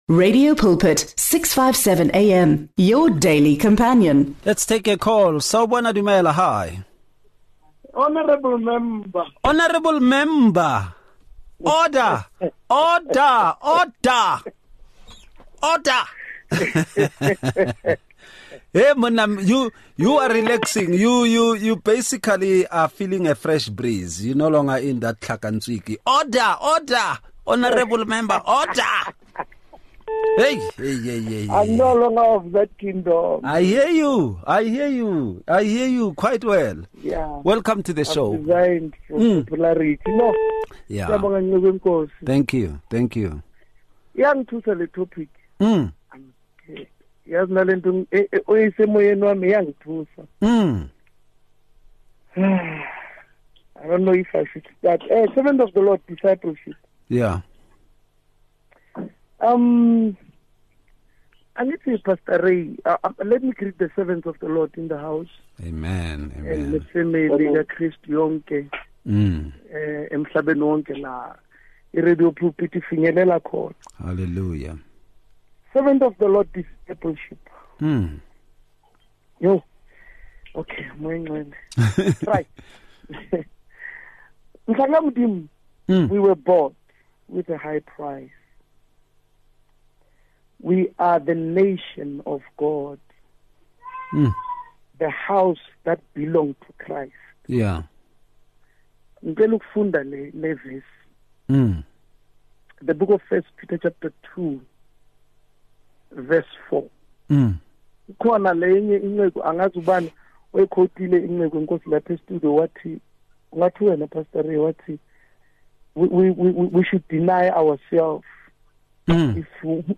The discussion brings together diverse pastoral perspectives, enriching the conversation with practical and theological insights. The panel examines foundational principles of discipleship, emphasizing its importance in nurturing spiritual growth and community engagement. Through thoughtful dialogue, they address challenges and strategies for effective discipleship within contemporary church settings.